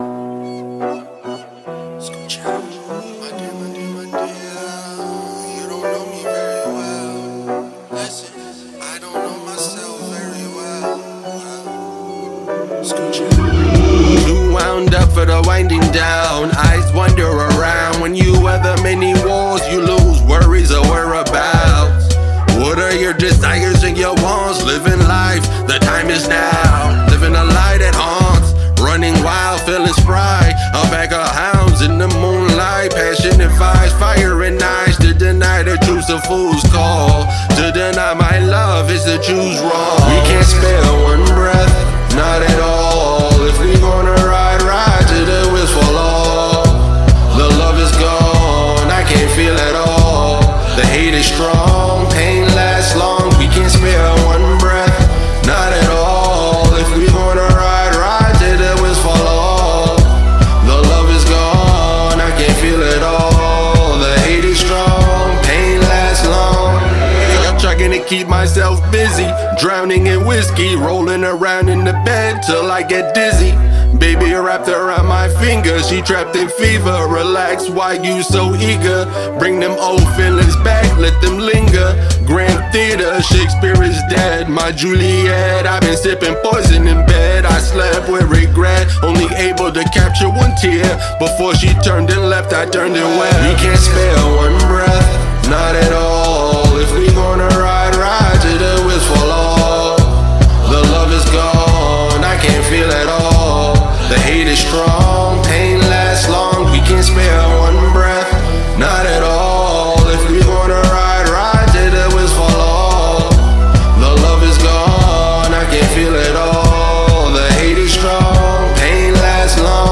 emo